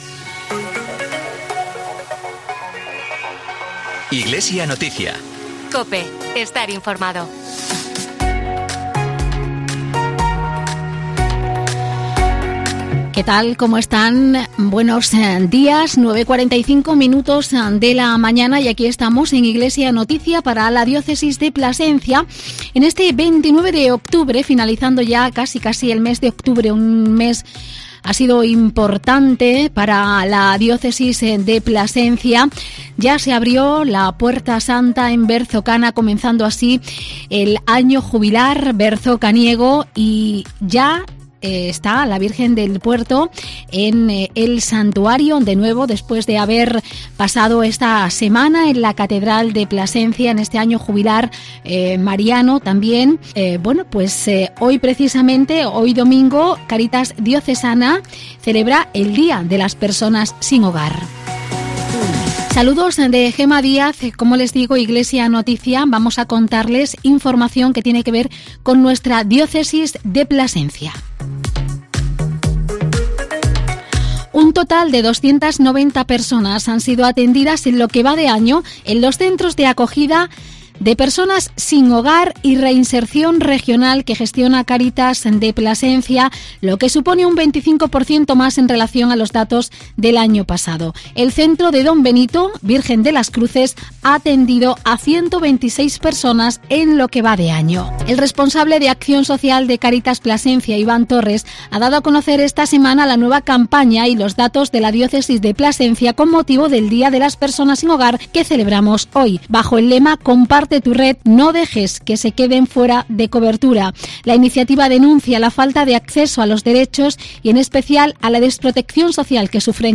AUDIO: Informativo semanal diocesano. Con motivo del Día de las personas sin hogar, damos a conocer la campaña "Comparte tu red"